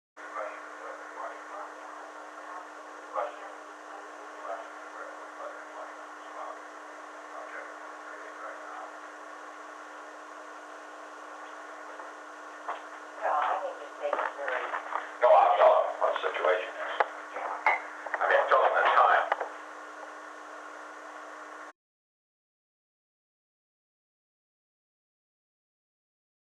Secret White House Tapes
Conversation No. 238-2
Location: Camp David Hard Wire
The President met with Thelma C. (“Pat”) Nixon.